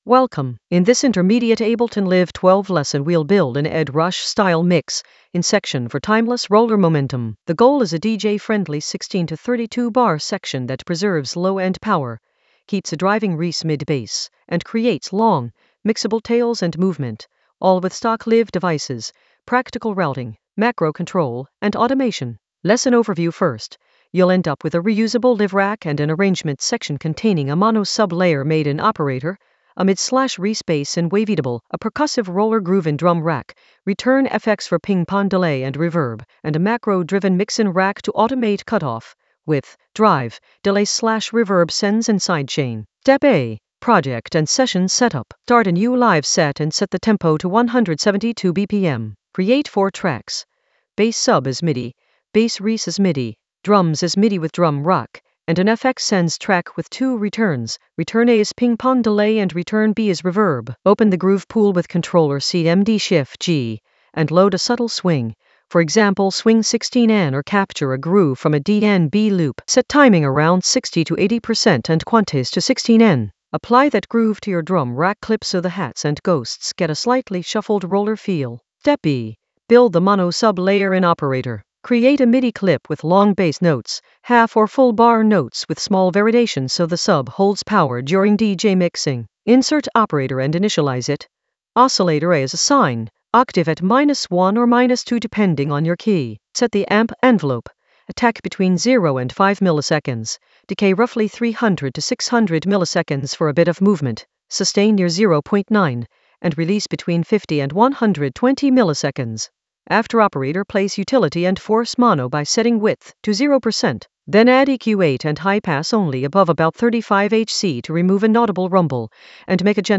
An AI-generated intermediate Ableton lesson focused on Ed Rush mix-in section in Ableton Live 12 for timeless roller momentum in the Sound Design area of drum and bass production.
Narrated lesson audio
The voice track includes the tutorial plus extra teacher commentary.